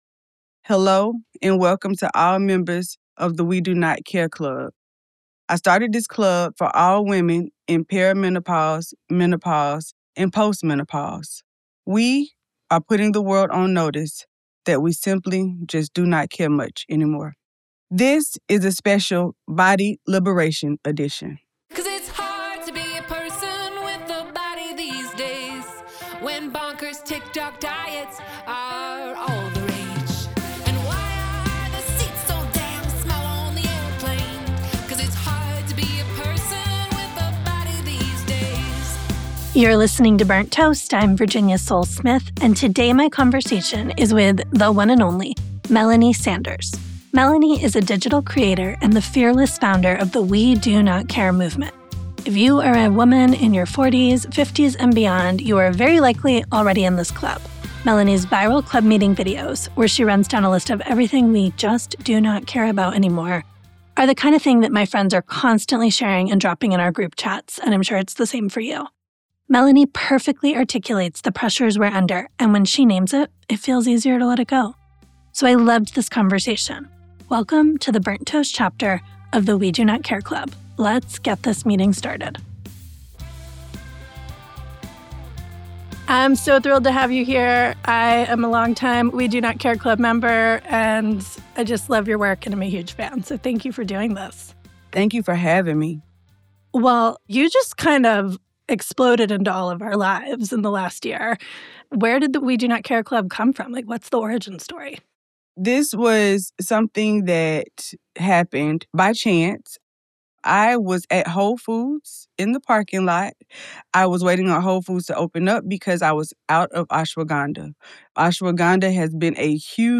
So I loved this conversation.